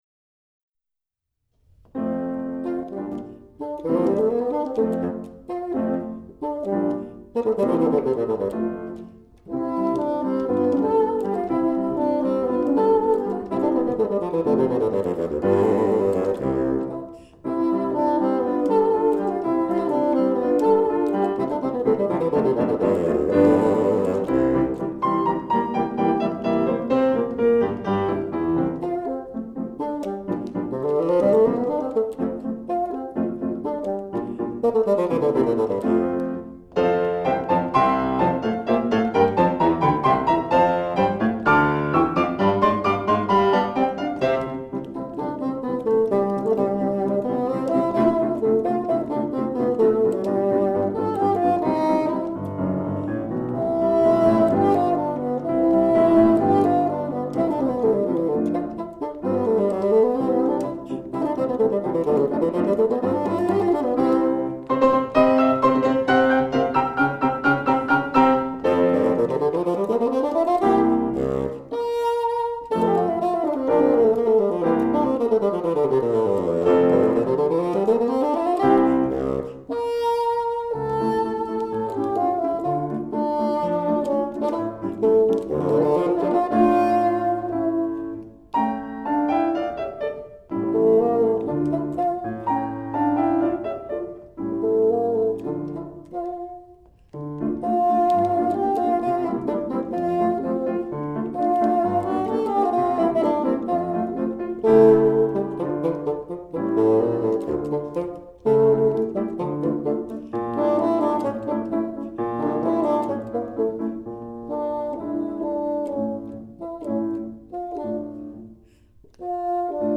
Piano
過去の演奏のご紹介